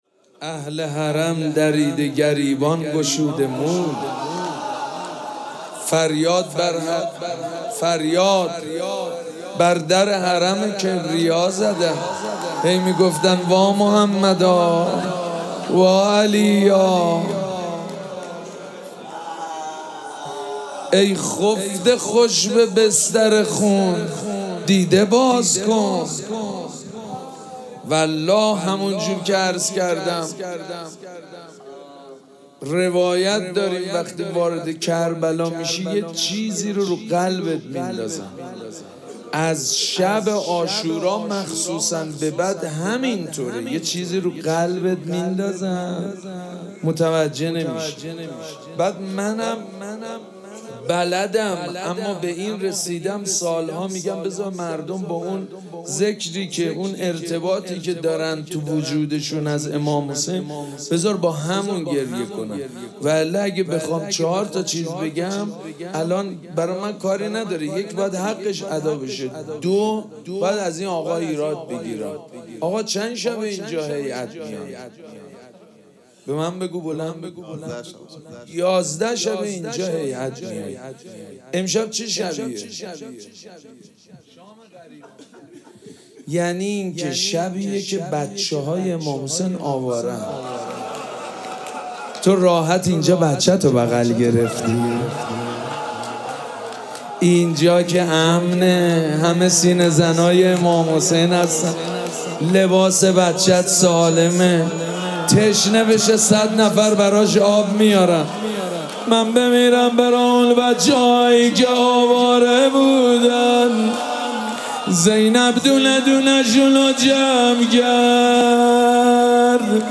مراسم عزاداری شام غریبان محرم الحرام ۱۴۴۷
روضه
مداح
حاج سید مجید بنی فاطمه